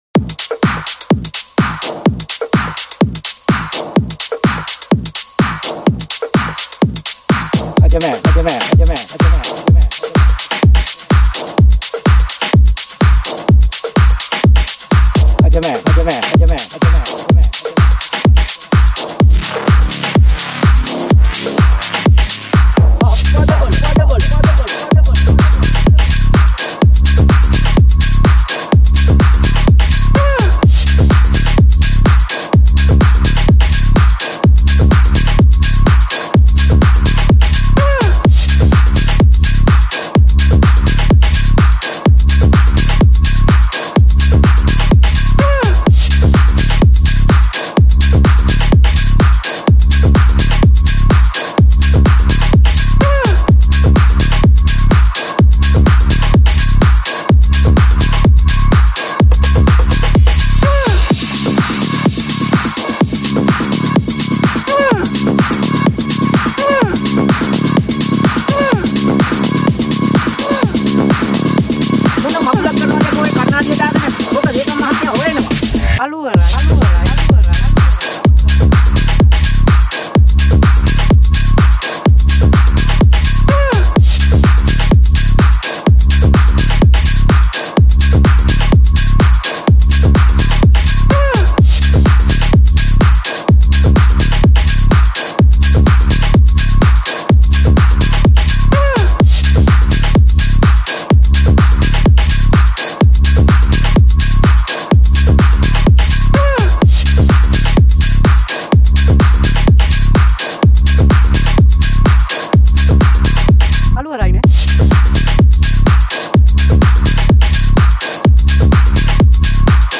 TECH HOUSE
BPM - 126